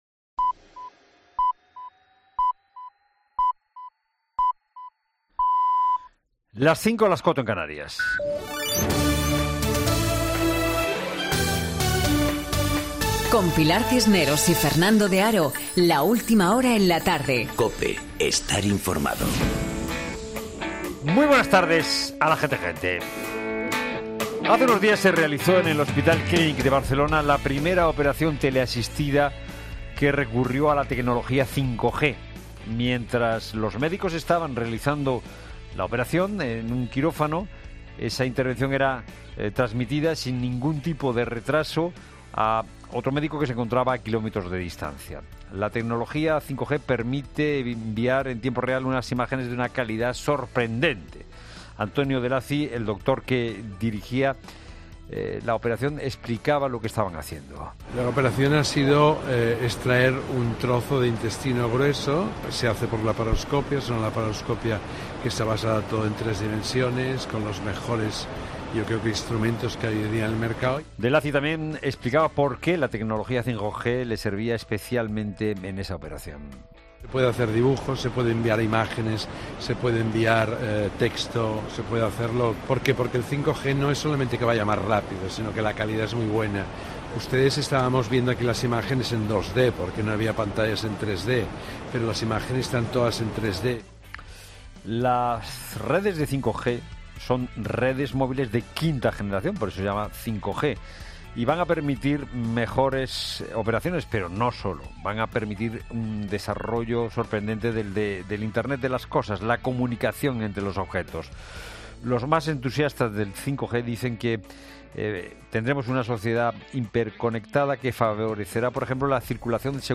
monólogo de las 17.00 horas